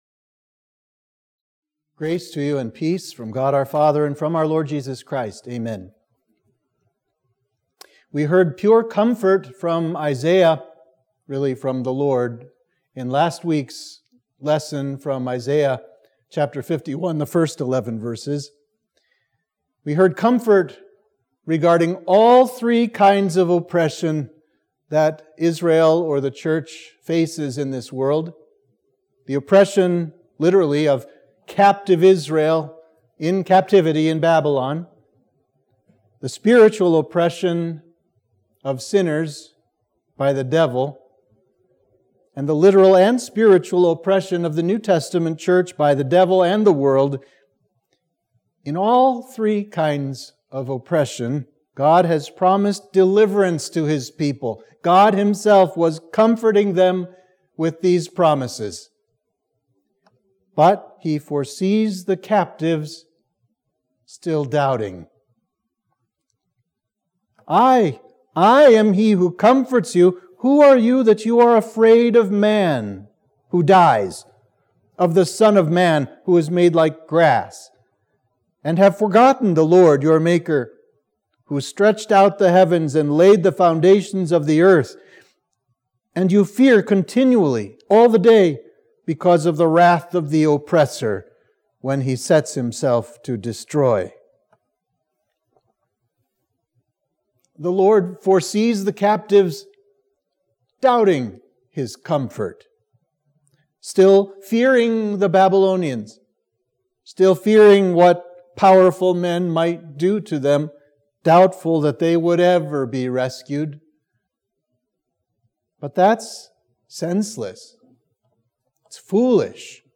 Sermon for Midweek of Trinity 10